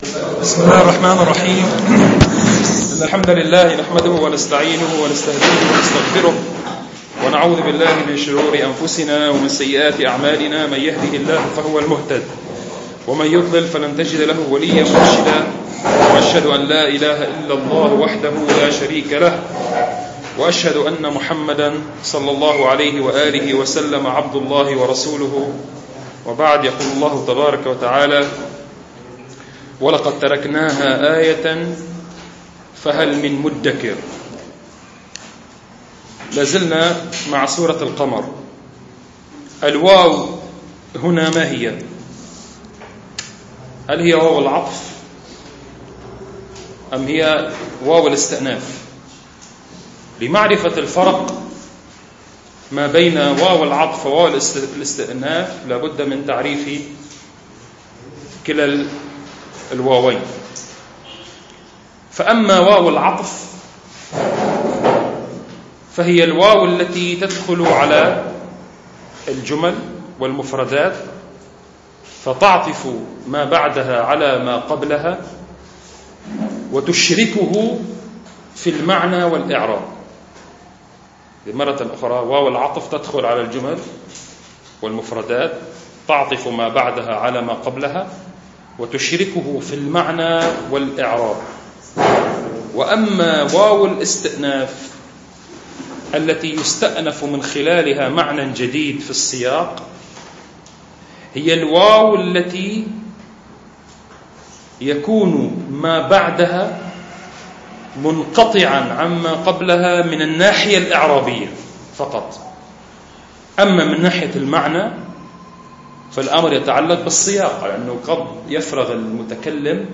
المكان : مبنى جماعة عباد الرحمن